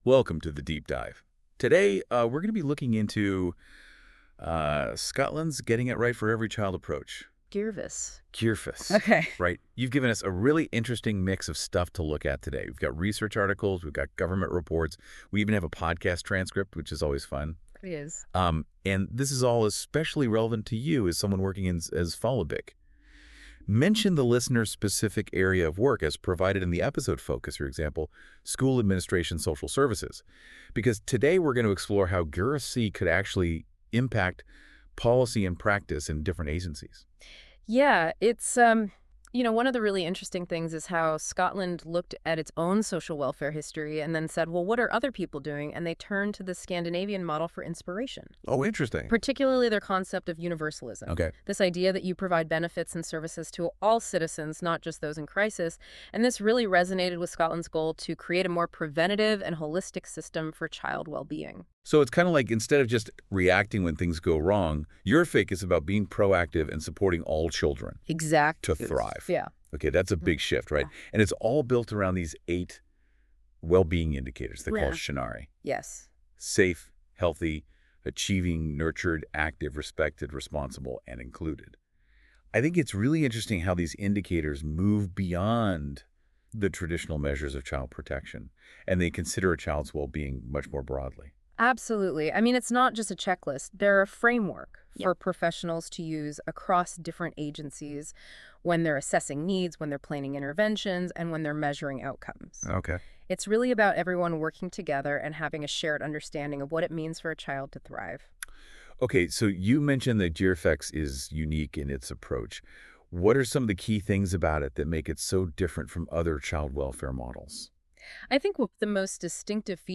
This is an autogenerated "Deep Dive" AI-podcast by Google based on the sources of this report.